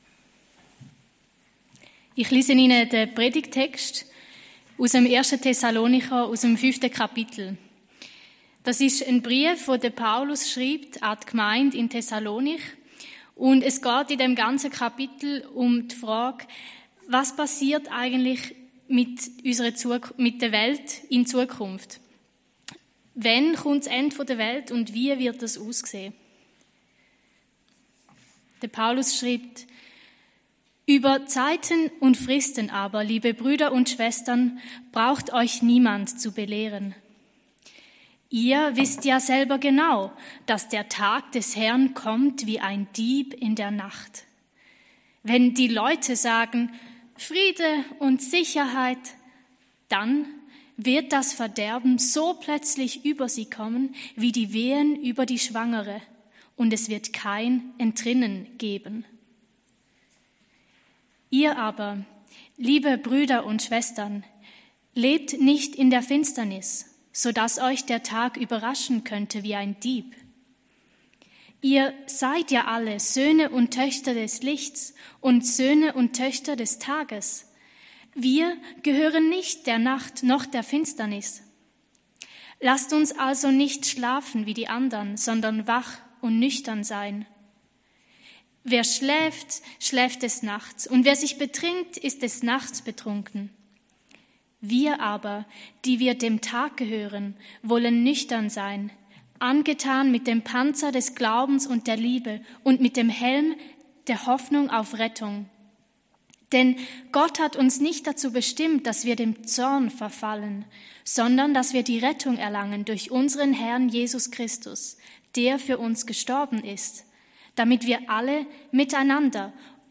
Liedpredigt